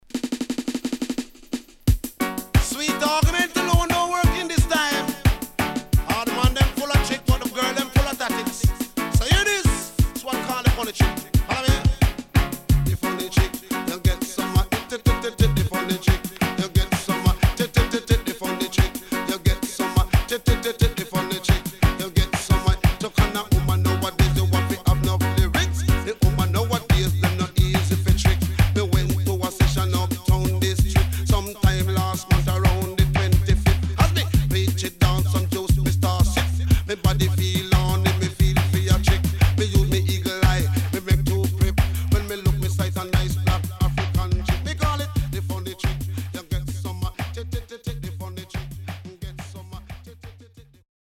HOME > DISCO45 [DANCEHALL]  >  COMBINATION
Good Combination & Nice Deejay
SIDE B:所々チリノイズがあり、少しプチノイズ入ります。